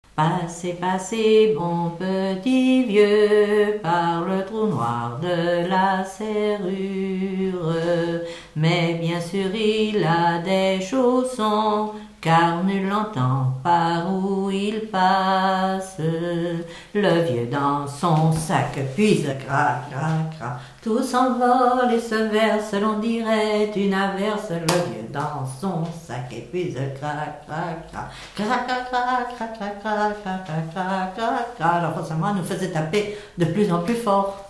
enfantine : lettrée d'école
Pièce musicale inédite